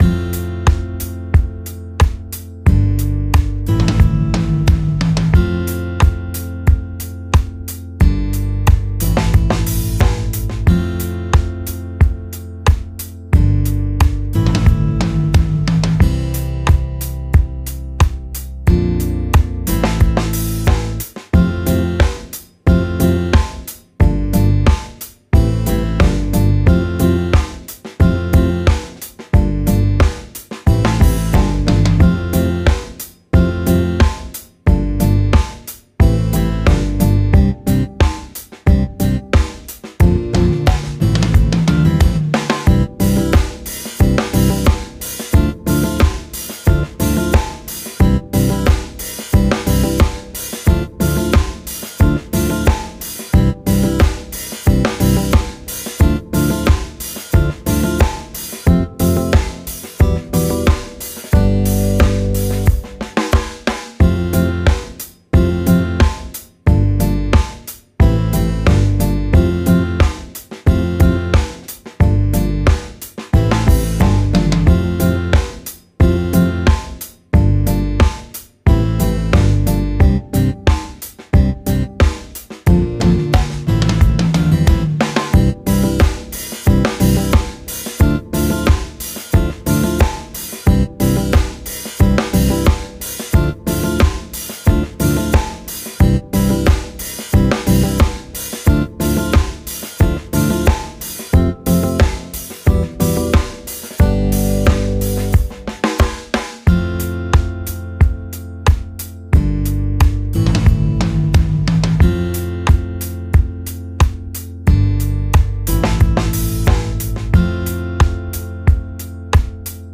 Dub,Reggae,instrumental